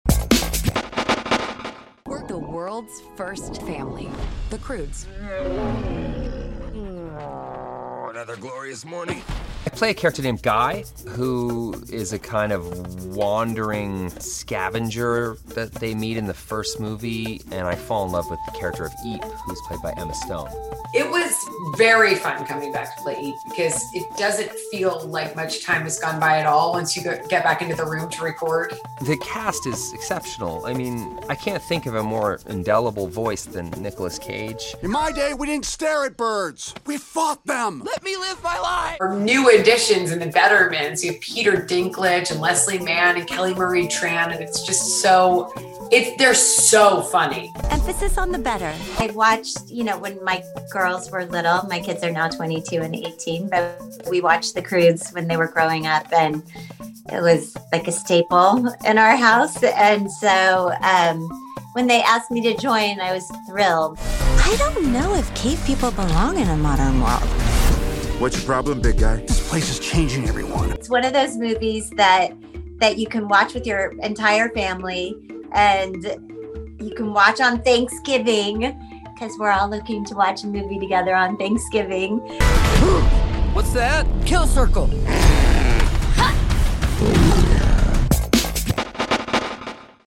We sat down with the animated film's stars Guy (Reynolds) and Eep (Stone) to hear about their return to the project after 7 years since the first flick and newcomer Mann on what it was like joining the movie she and her kids fell in love with when the children were young.